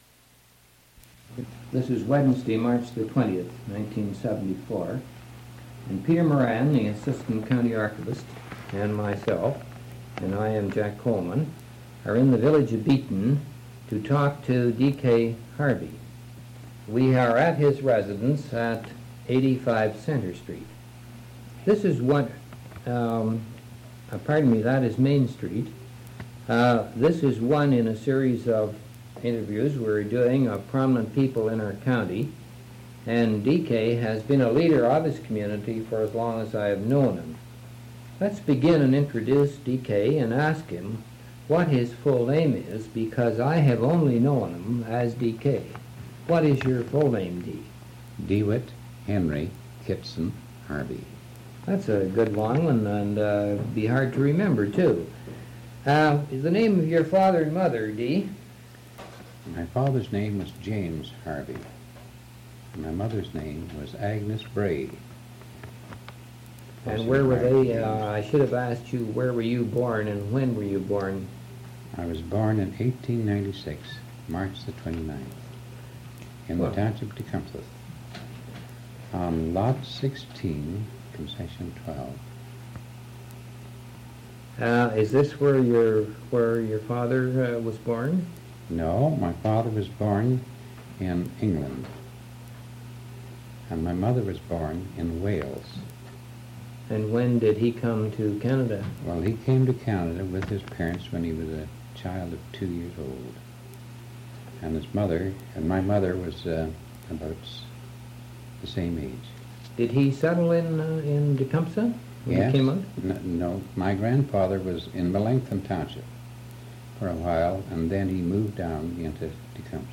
Oral History